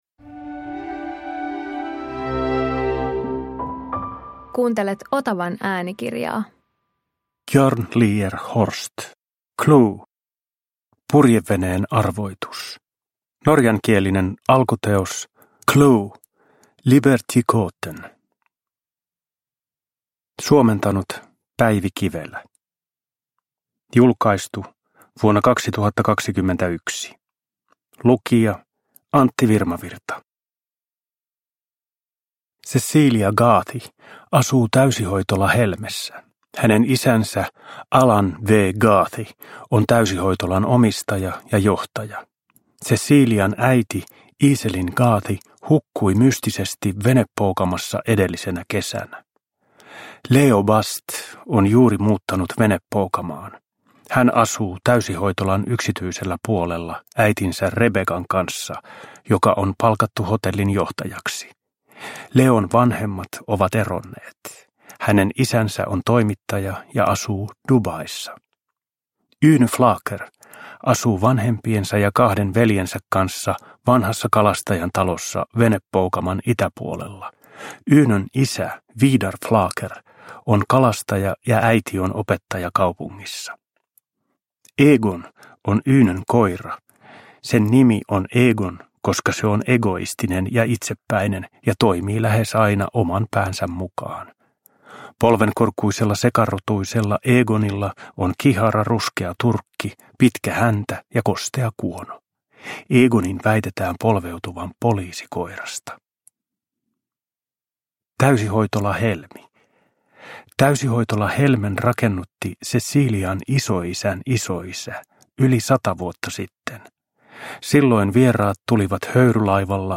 CLUE - Purjeveneen arvoitus – Ljudbok – Laddas ner
Uppläsare: Antti Virmavirta